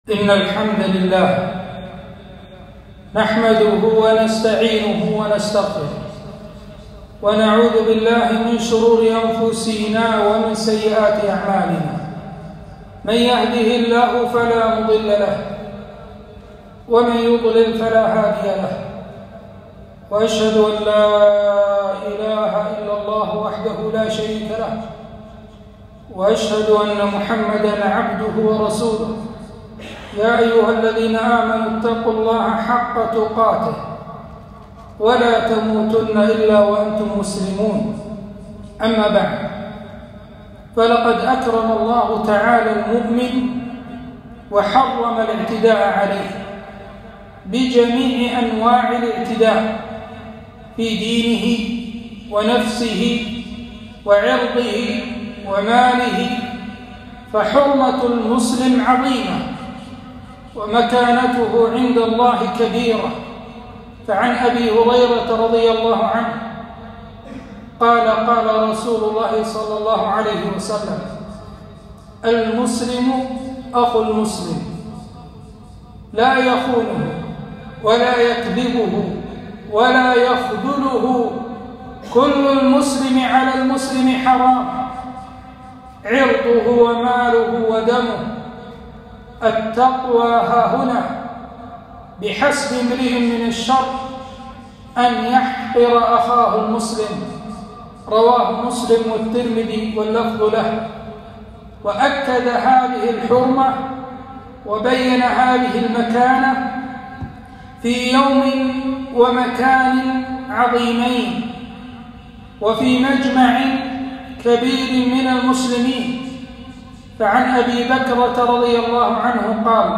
خطبة - حرمة قتل النفس - دروس الكويت